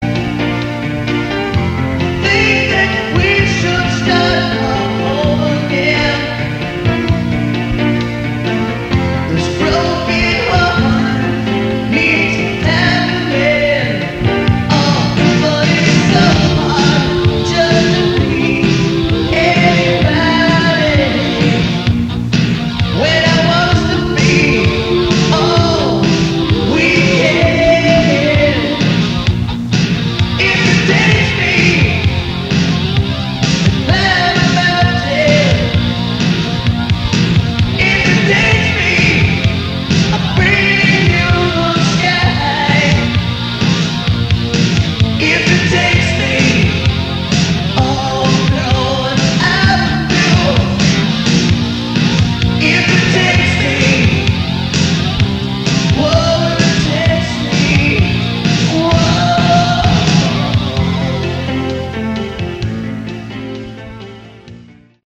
Category: Hard Rock
lead vocals